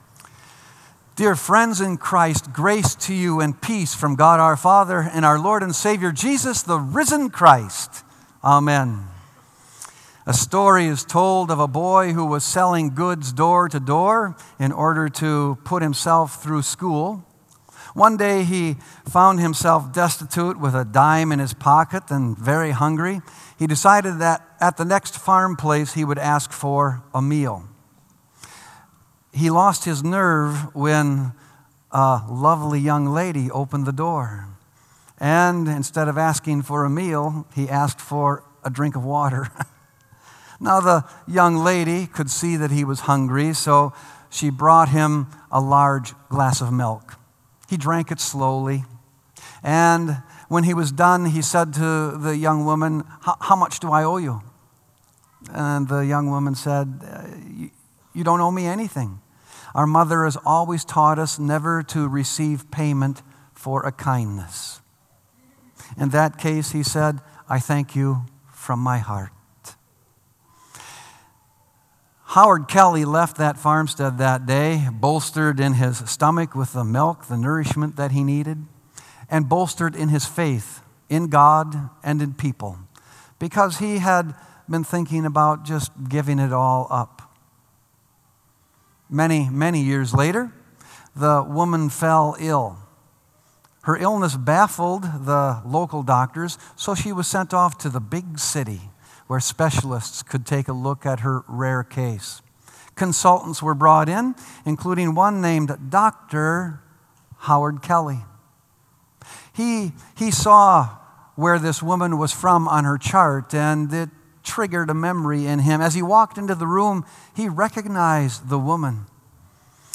Sermon “Glory Like No Other”